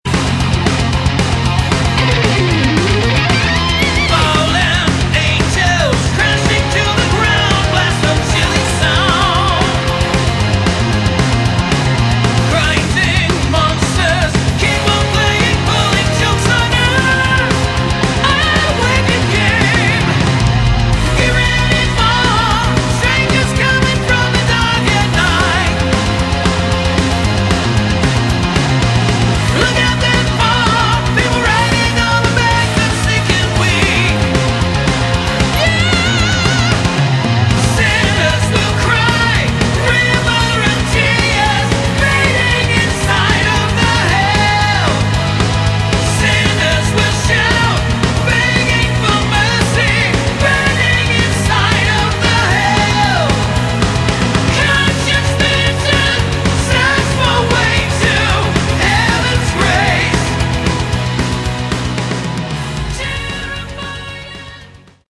Category: Melodic Metal
lead and backing vocals
guitars, bass, keyboards
drums